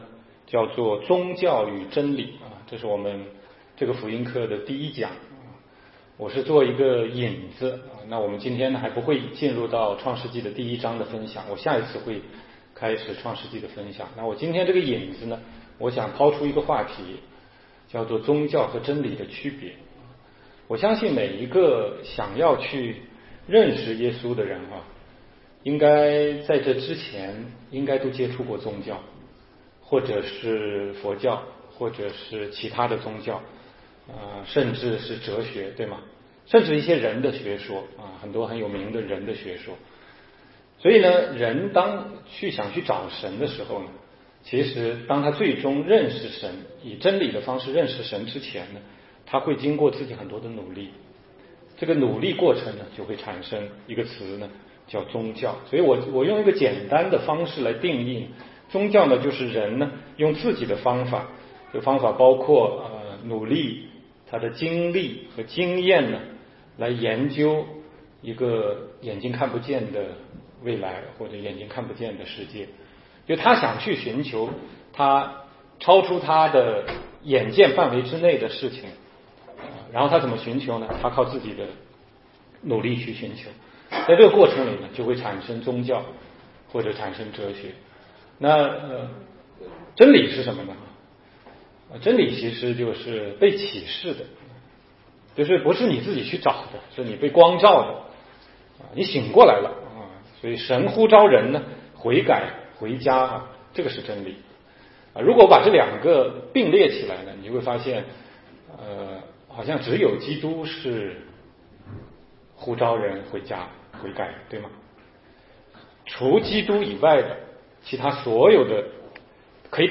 16街讲道录音 - 宗教与真理